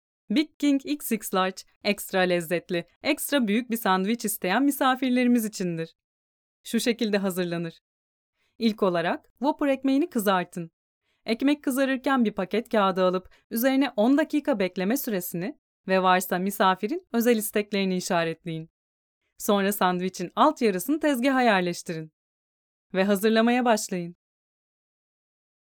Female
Warm, friendly, deep, peaceful, feminine, professional, maternal.
E-Learning
All our voice actors have professional broadcast quality recording studios.